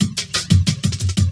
17 LOOP09 -L.wav